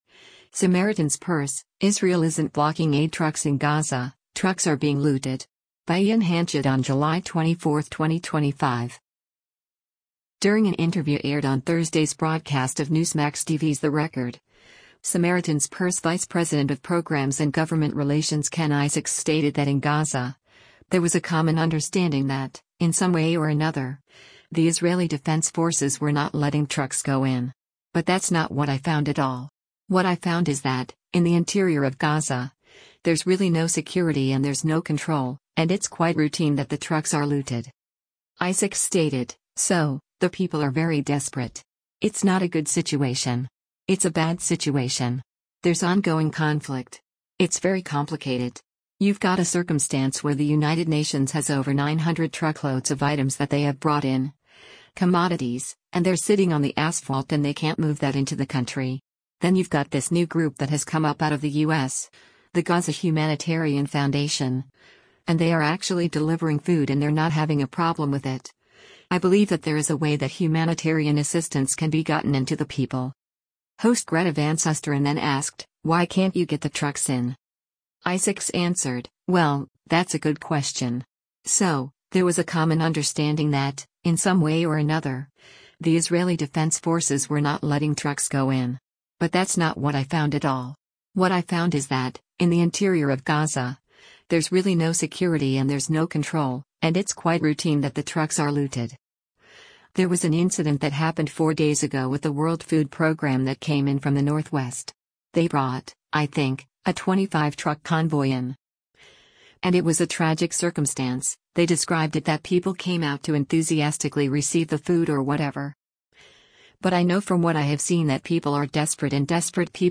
Host Greta Van Susteren then asked, “Why can’t you get the trucks in?”